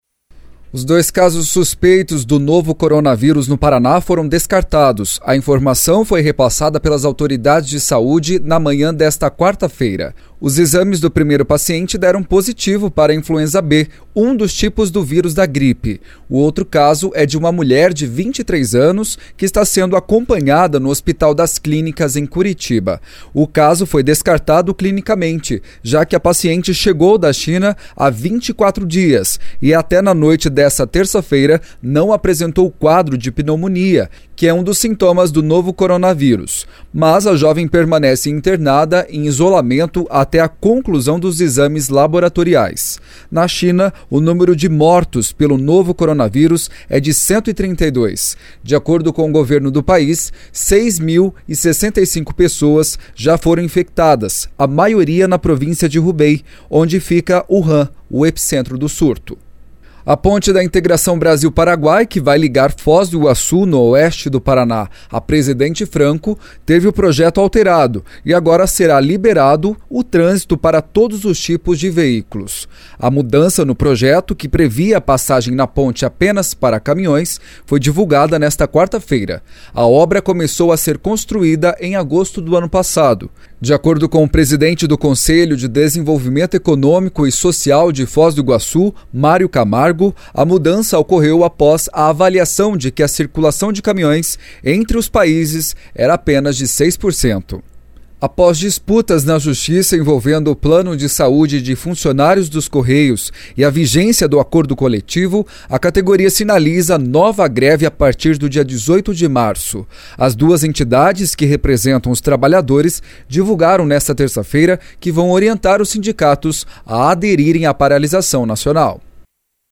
Giro de Notícias COM TRILHA